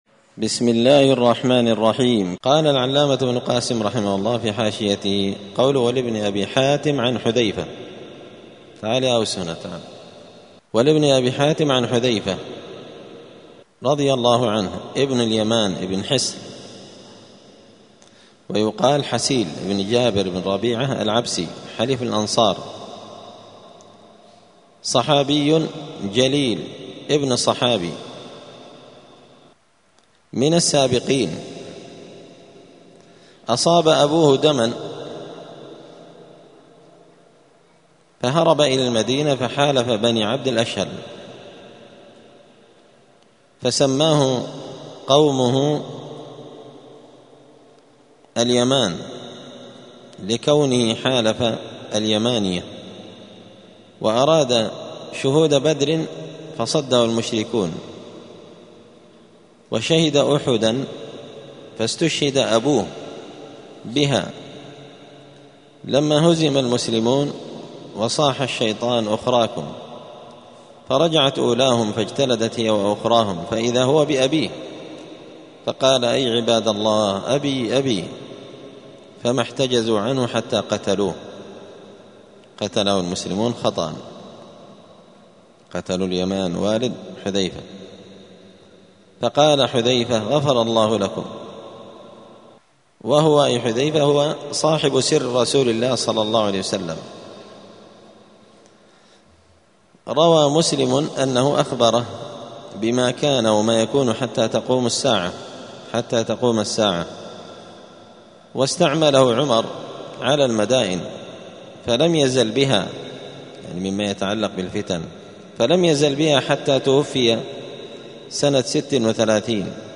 دار الحديث السلفية بمسجد الفرقان بقشن المهرة اليمن